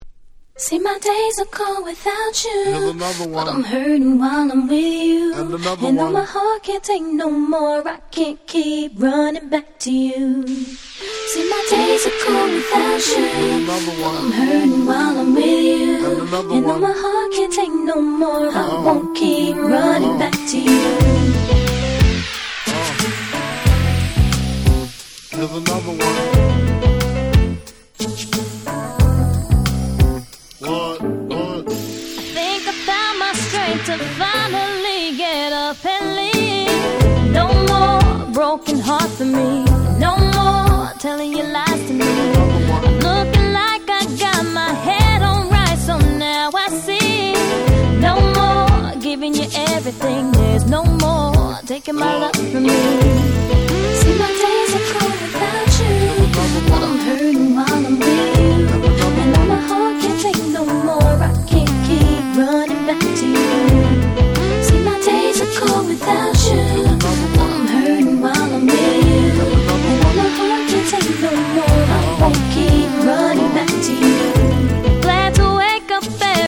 ヒップホップソウル